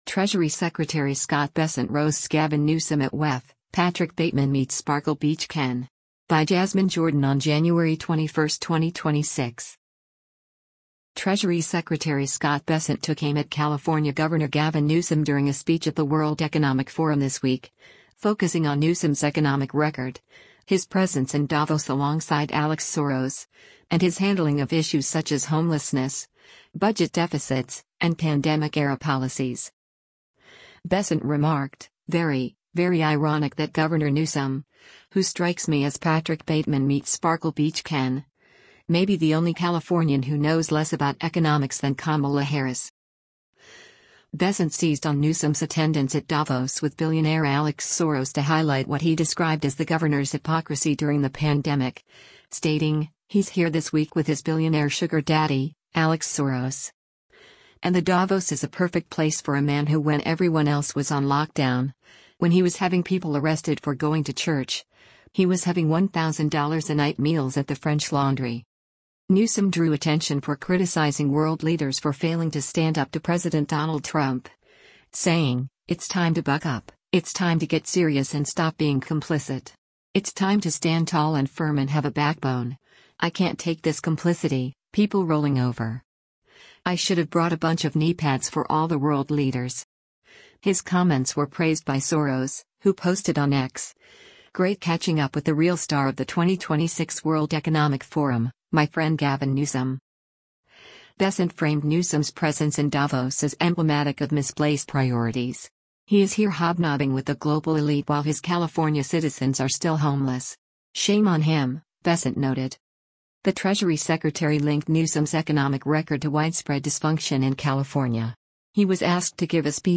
DAVOS, SWITZERLAND - JANUARY 20: US Treasury Secretary Scott Bessent speaks at the 56th Wo